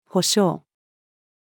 保障-female.mp3